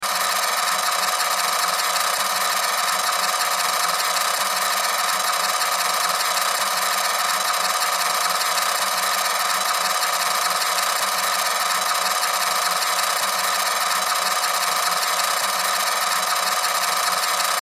Film Projector Sound Effect
This old movie projector sound effect captures the mechanical whirring, clicking, and rolling of a vintage film projector.
Film-projector-sound-effect.mp3